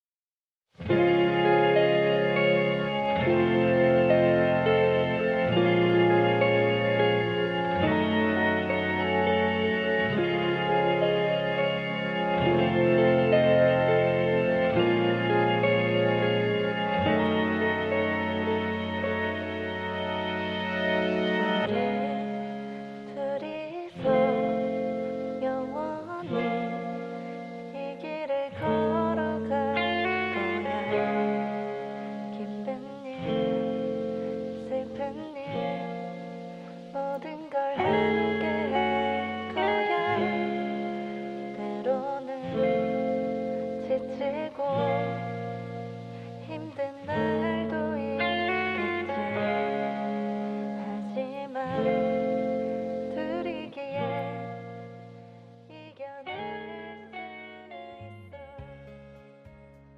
음정 -1키
장르 가요 구분
가사 목소리 10프로 포함된 음원입니다